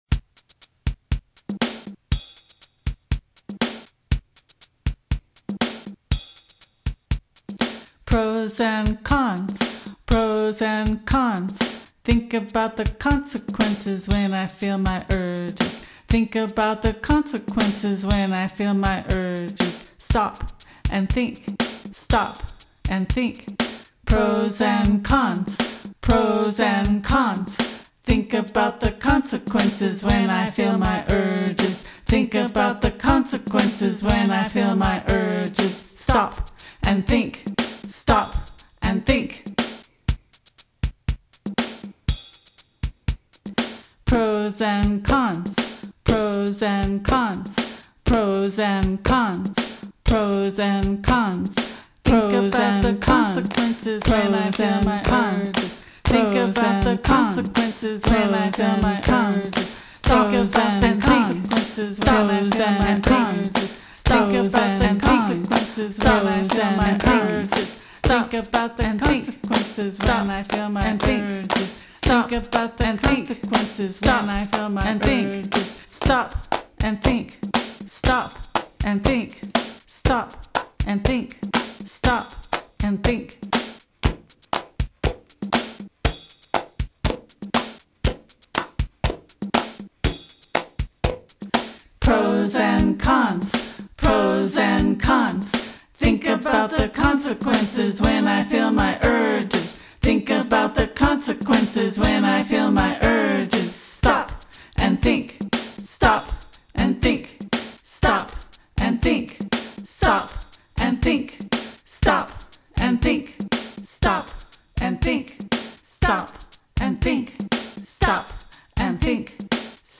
This simple chant reinforces that moment of pause and reflection in a way that’s easy to remember and apply.
studio recording of the song.